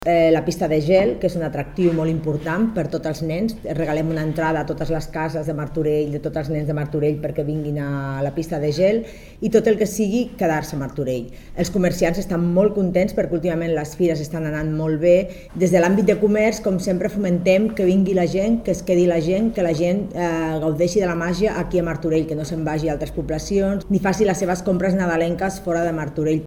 Rosa Cadenas, regidora de Promoció Econòmica, Comerç i Turisme